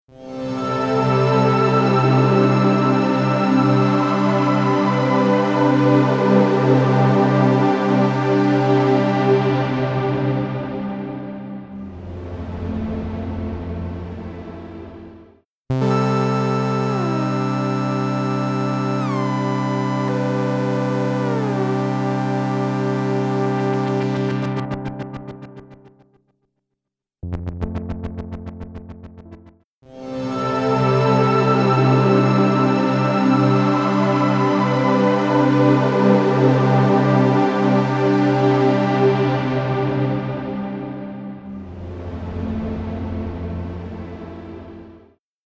Massive Otherworldly Reverb
Blackhole | Synth | Preset: When We Are Both Cats
Blackhole-Eventide-Synth-Poly-When-We-Are-Both-Cats.mp3